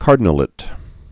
(kärdn-ə-lĭt, -lāt, kärdnə-)